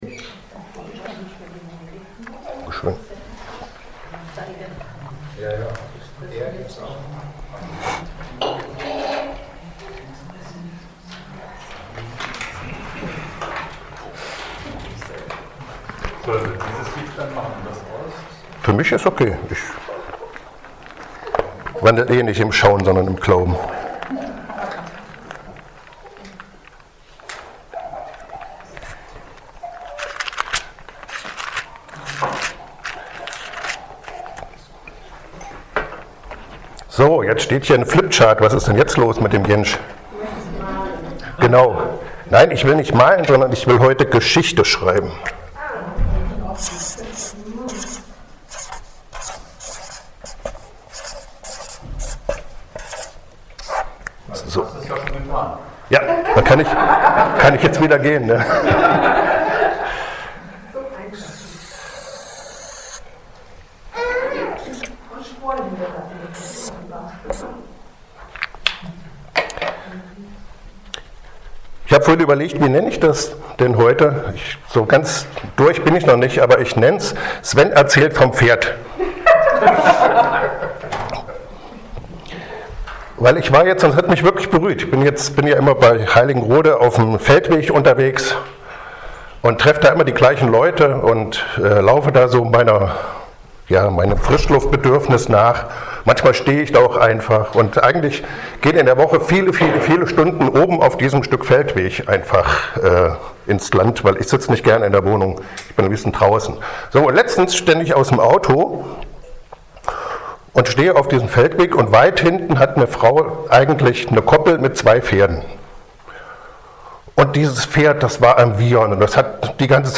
Dienstart: Externe Prediger